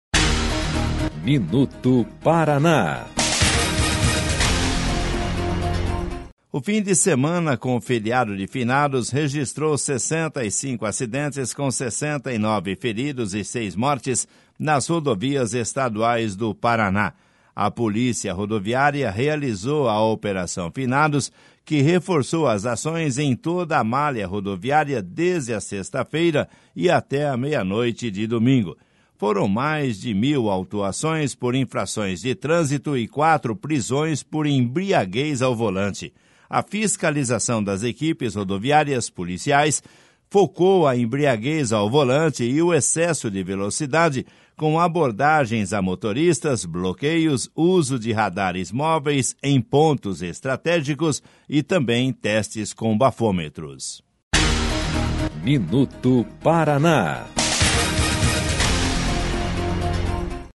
MINUTO PARANÁ - BOLETIM DA POLÍCIA RODOVIÁRIA - OPERAÇÃO FINADOS